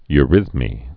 (y-rĭthmē)